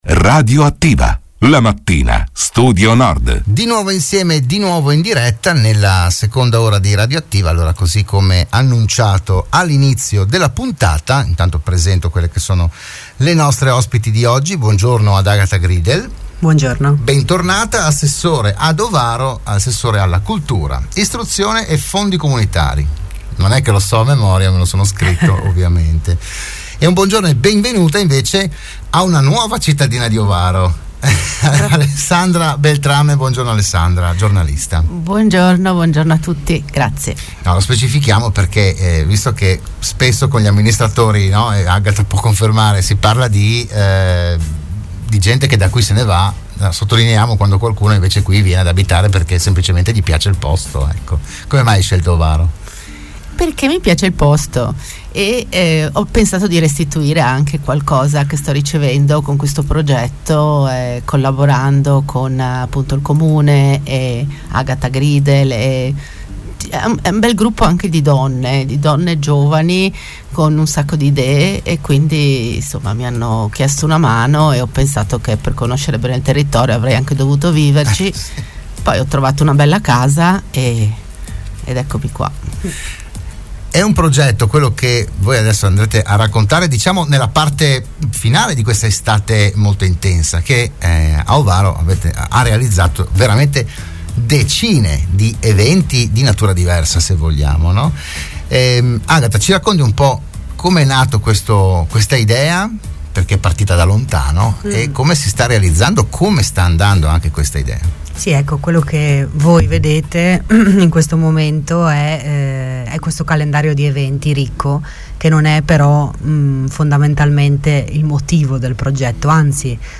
in una conferenza stampa aperta al pubblico che ha avuto luogo nel Centro socio culturale del capoluogo della Val Degano